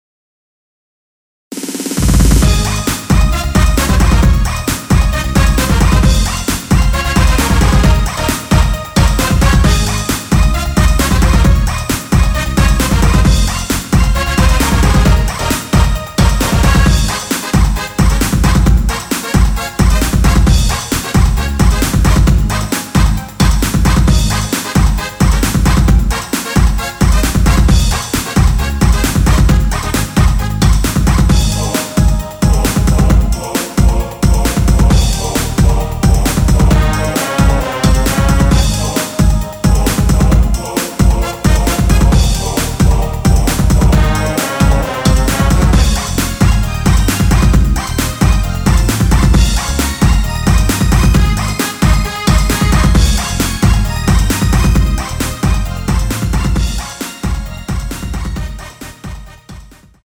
-1)내린 MR입니다.
전주가 길어서 원곡의 20초 부터 시작하게 제작하였습니다.
Ab
◈ 곡명 옆 (-1)은 반음 내림, (+1)은 반음 올림 입니다.
앞부분30초, 뒷부분30초씩 편집해서 올려 드리고 있습니다.